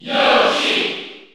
Category: Crowd cheers (SSBU) You cannot overwrite this file.
Yoshi_Cheer_French_NTSC_SSBU.ogg.mp3